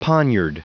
Prononciation du mot poniard en anglais (fichier audio)
Prononciation du mot : poniard